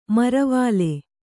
♪ maravāle